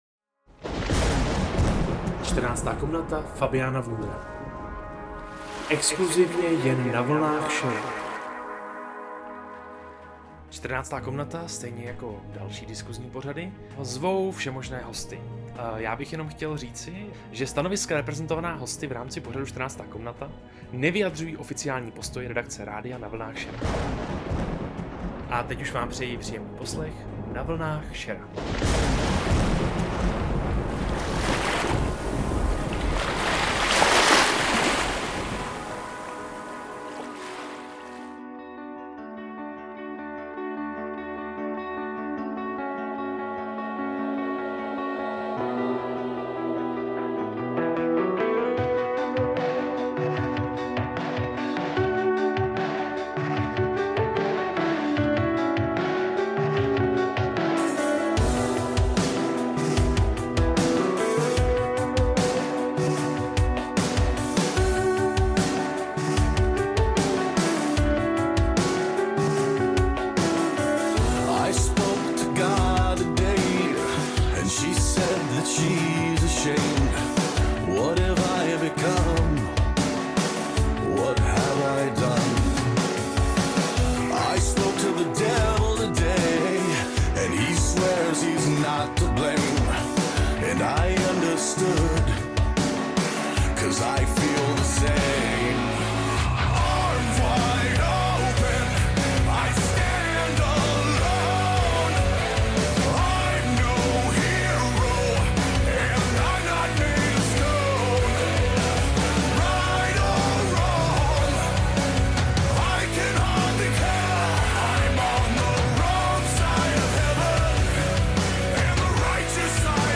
Nejenom na to se soustředil rozhovor s mágem noční hlídky